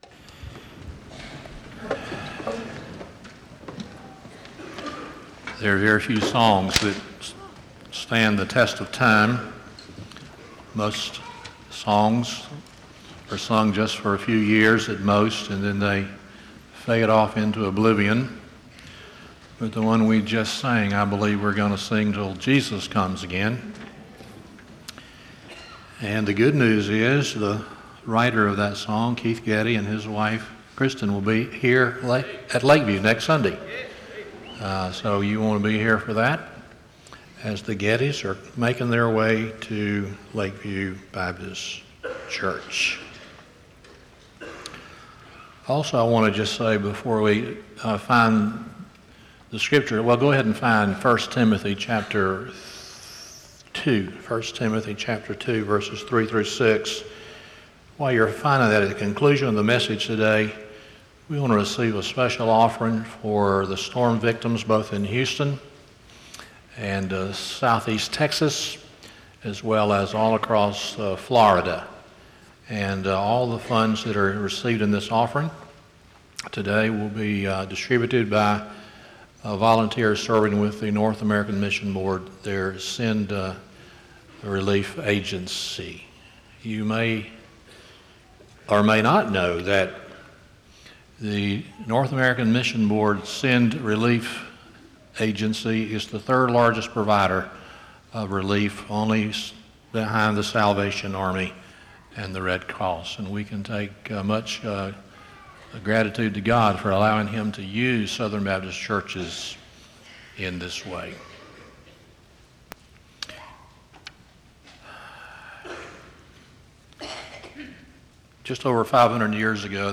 1 Timothy 2:3-6 Service Type: Sunday Morning 1